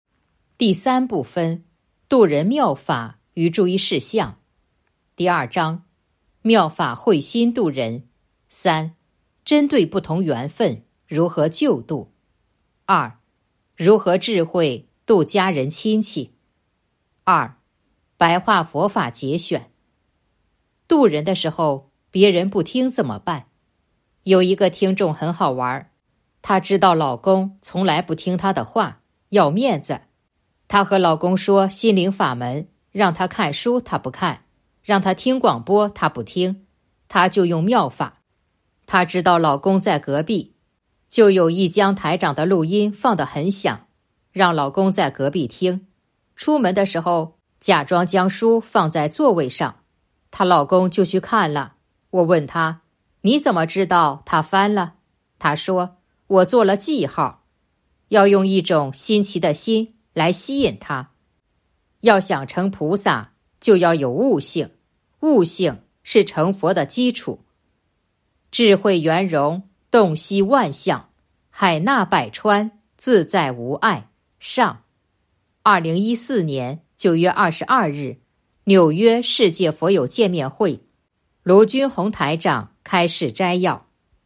白话佛法节选《弘法度人手册》【有声书】